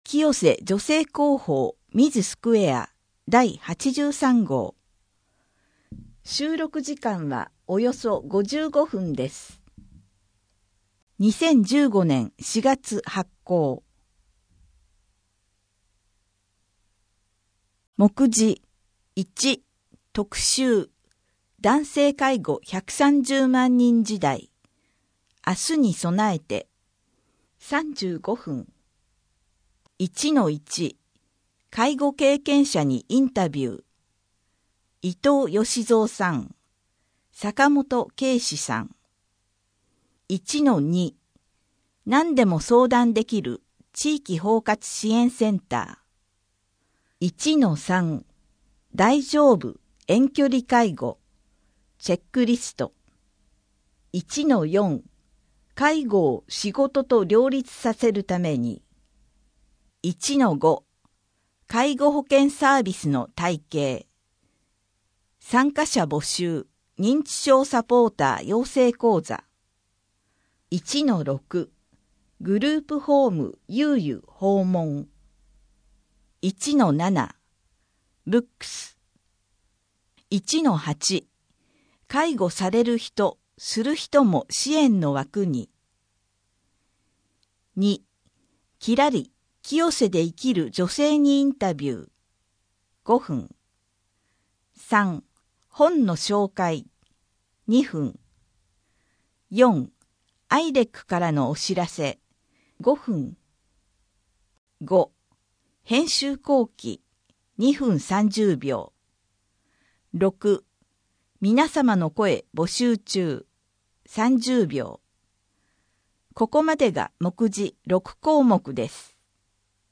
編集後記など 声の広報 声の広報は清瀬市公共刊行物音訳機関が制作しています。